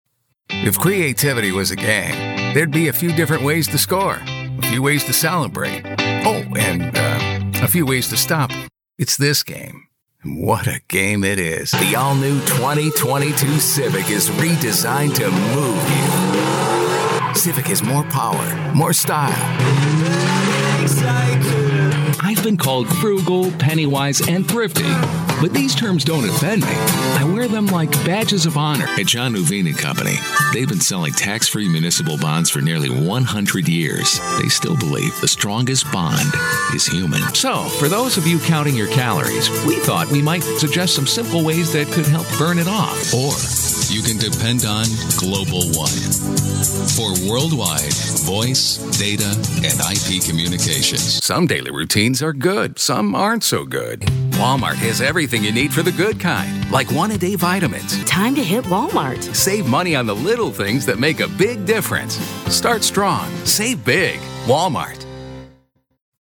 Engels (Amerikaans)
Diep, Natuurlijk, Opvallend, Vriendelijk, Warm
Commercieel